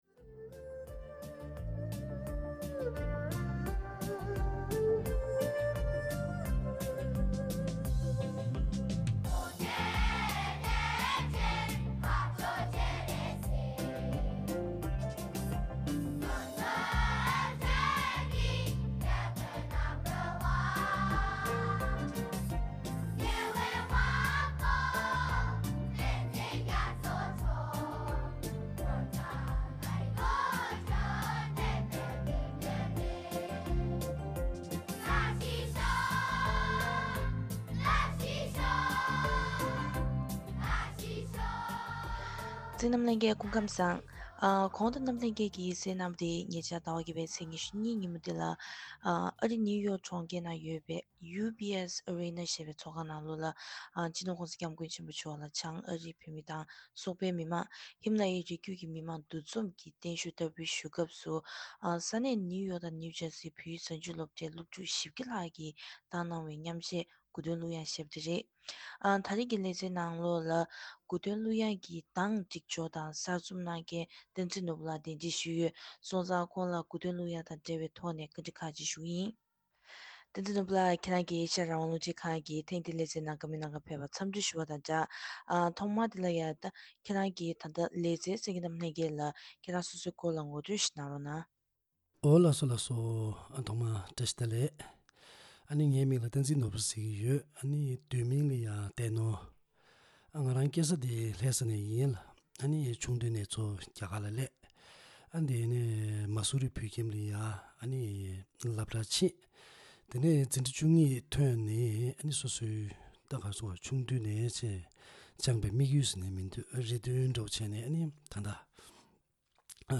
བཀའ་དྲི་ཞུས་པ་ཞིག་གཤམ་ལ་གསན་གནང་གི་རེད།